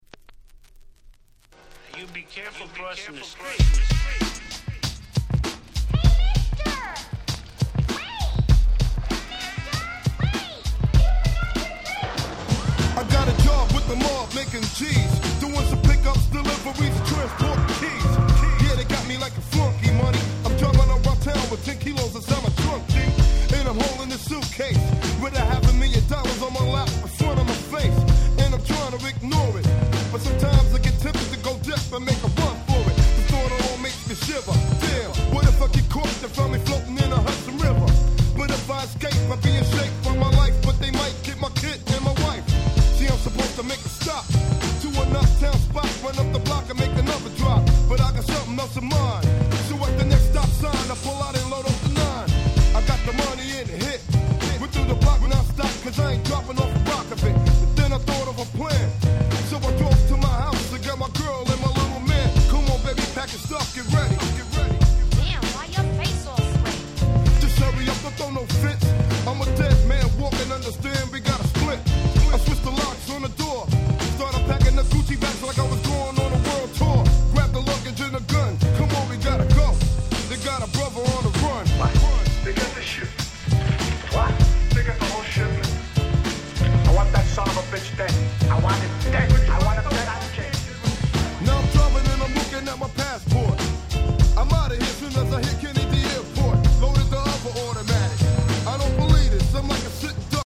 92' Smash Hit Hip Hop !!
スリリングなTrackに彼の真骨頂でもあるThugなストーリーテリングが異常に映えます！！